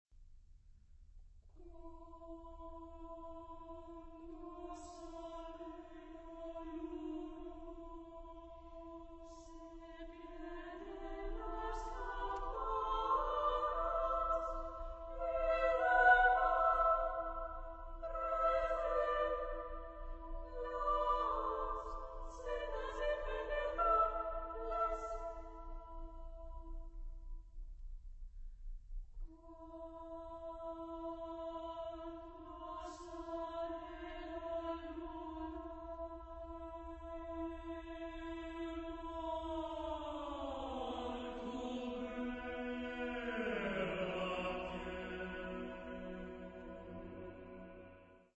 Genre-Style-Form: Secular ; Poem
Mood of the piece: contrasted ; rhythmic ; dissonant
Type of Choir: SSAATB  (6 mixed voices )
Soloist(s): Soprano (1)  (1 soloist(s))
Tonality: E tonal center